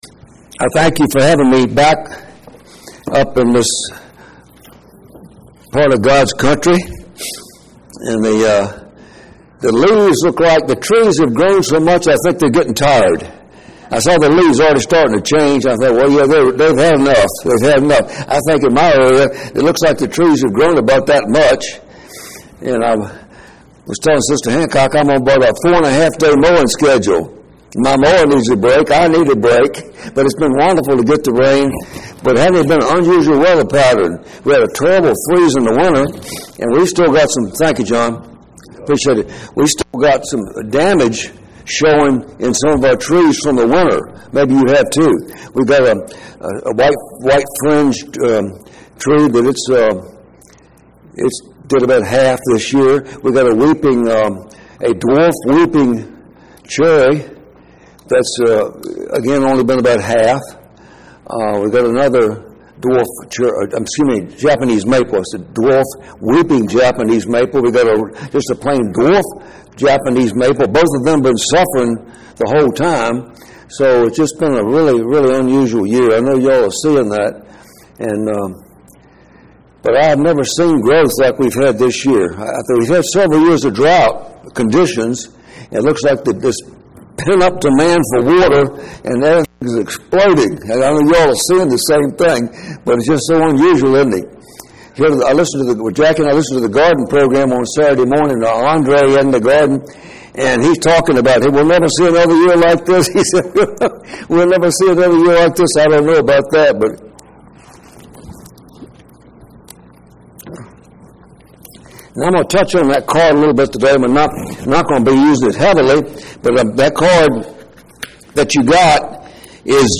Bible Study – We Need an Exit Plan